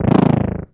bad_explosion1.wav